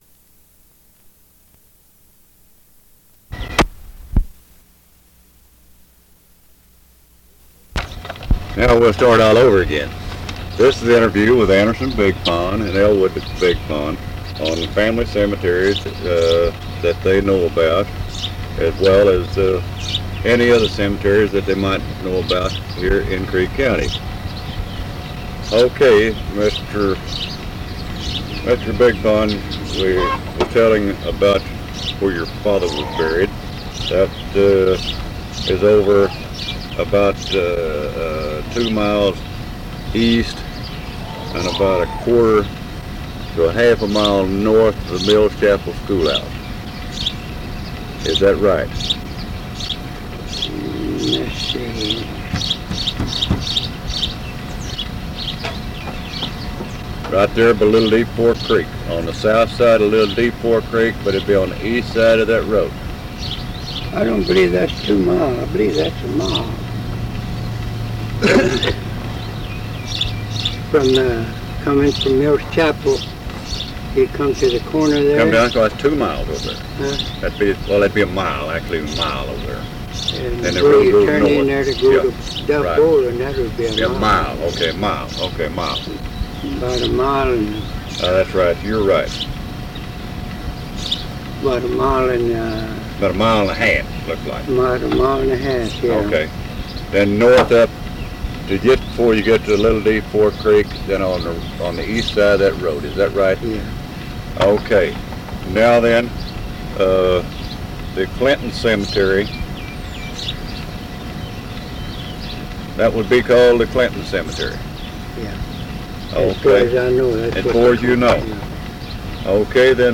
Oral History Archive | Family Histories